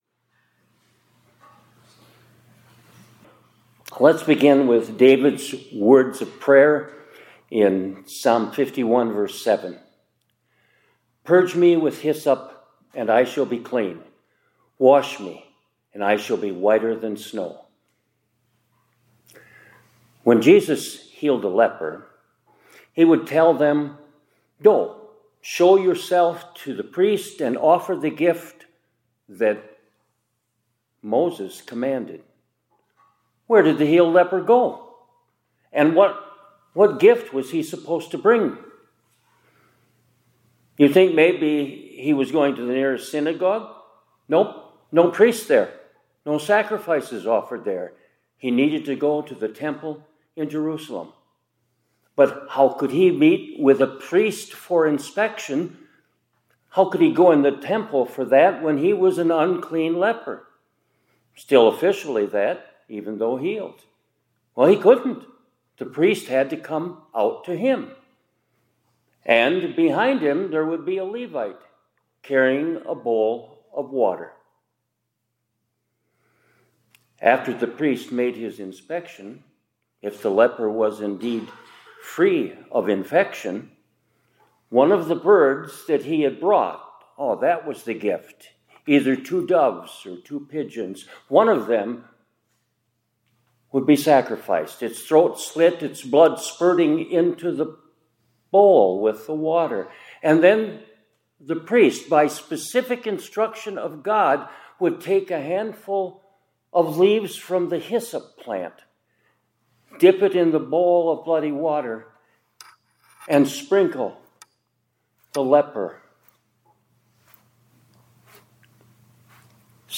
2026-03-26 ILC Chapel — Purge Me With Hyssop